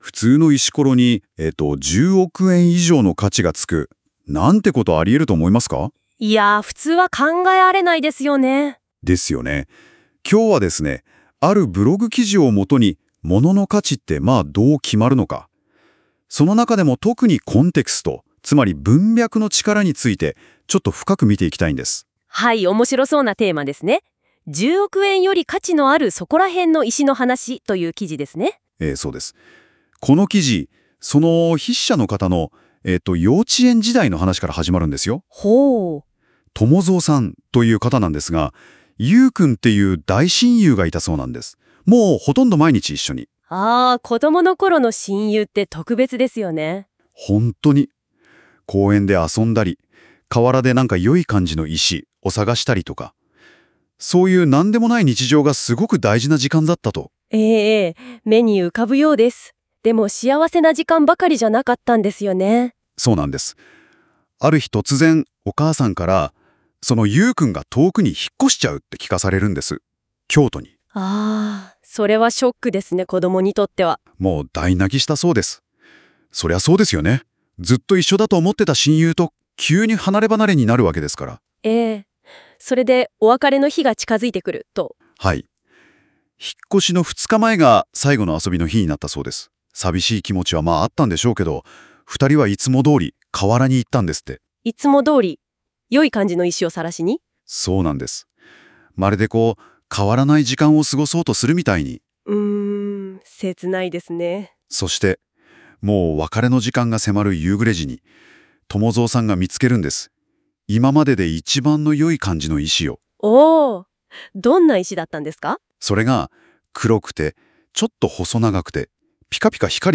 聞き手を引き込むように話してくれるんです。
「NotebookLM」 というAIツール。
•  最後まで自然に聞ける高品質な音声を生成してくれる